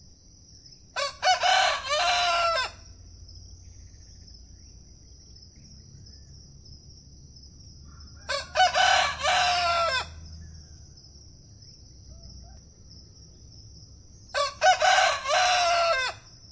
公鸡打鸣
描述：我们养的公鸡打鸣，非常响亮。 记录在iPhone，用Reaper处理。
标签： 公鸡 鸡鸣 农场 打鸣 鸡叫
声道单声道